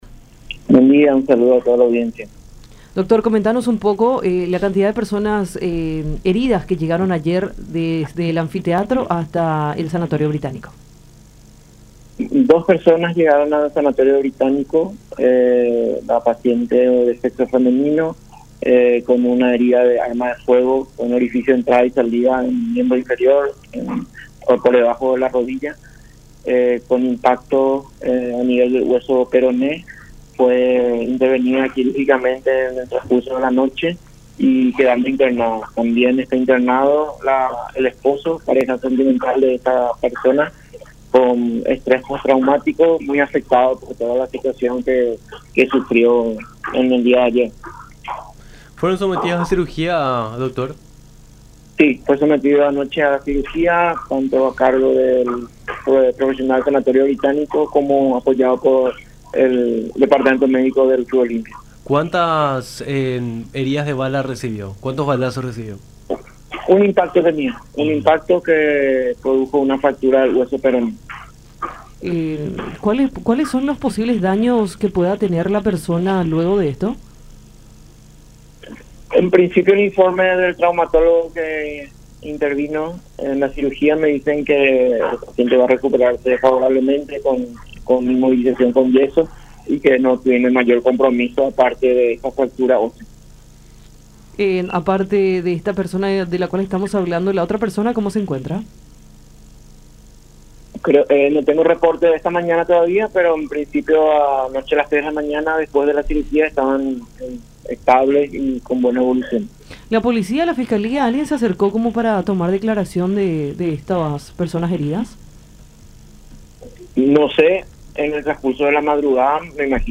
en conversación con Nuestra Mañana por La Unión.